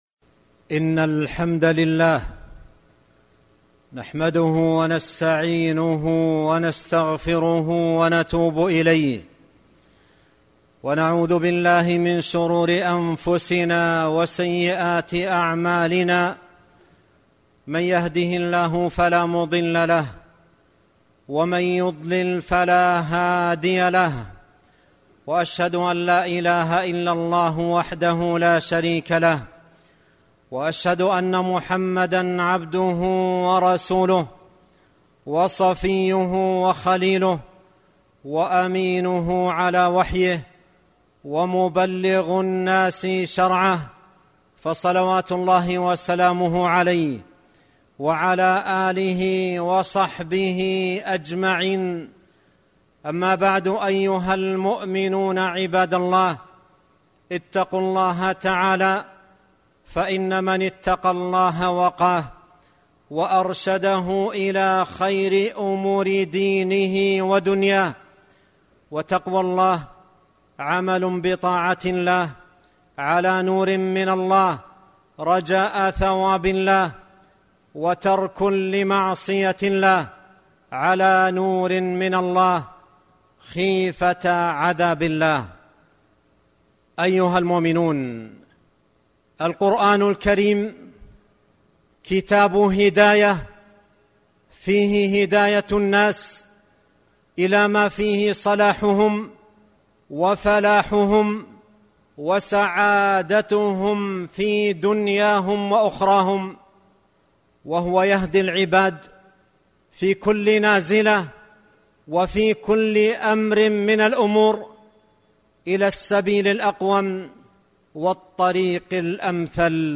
خطبة - من هدايات القرآن حول فيروس كورونا